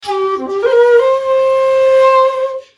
SONS ET LOOPS DE SHAKUHACHIS GRATUITS
Shakuhachi 47